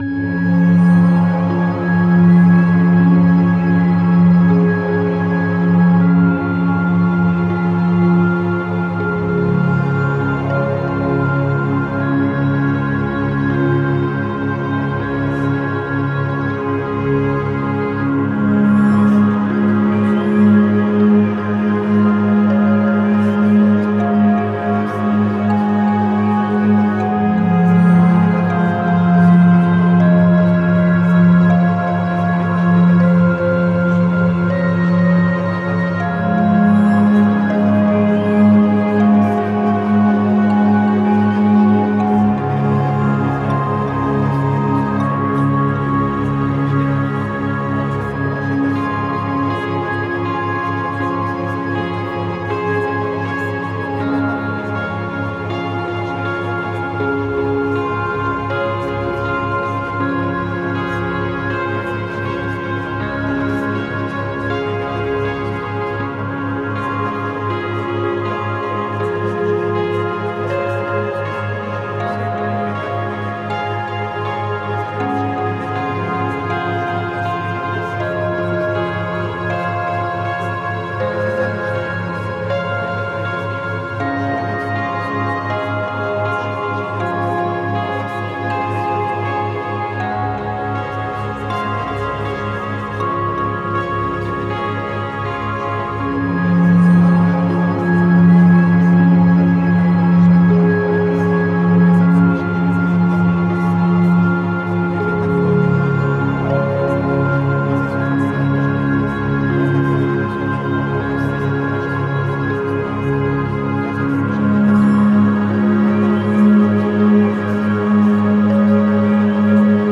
(Version RELAXANTE)
Alliage ingénieux de sons et fréquences curatives, très bénéfiques pour le cerveau.
Pures ondes thêta apaisantes 4Hz de qualité supérieure.
SAMPLE-Suggestion-hypnotique-relaxant.mp3